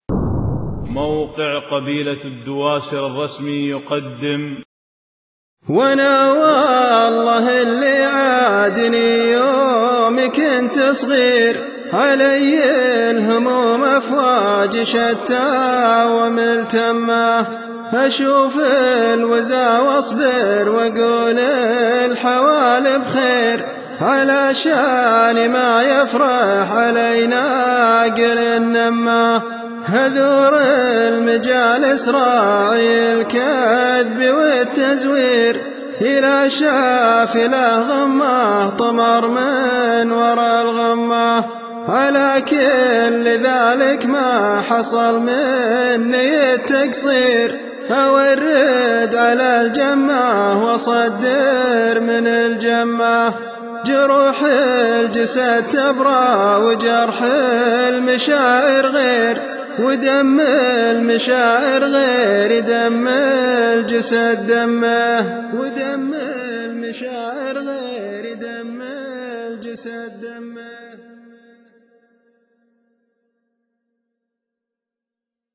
قصائد صوتيّة حصريّة للموقع